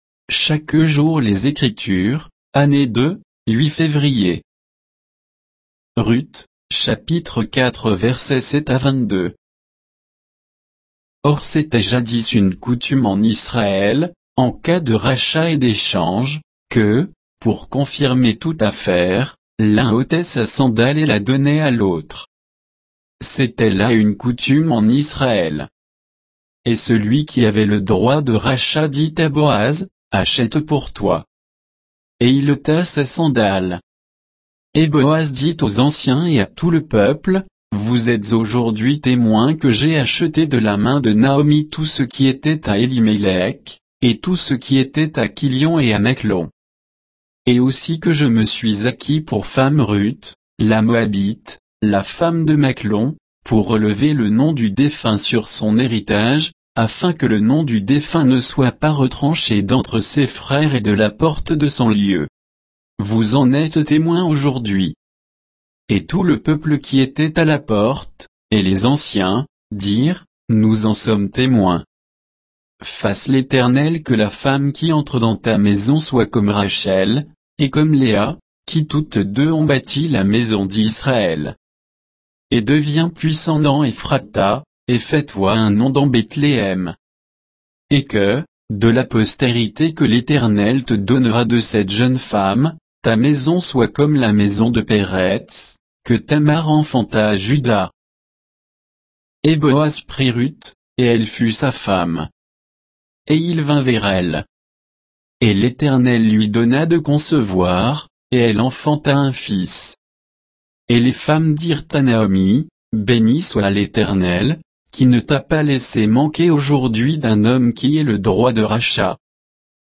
Méditation quoditienne de Chaque jour les Écritures sur Ruth 4, 7 à 22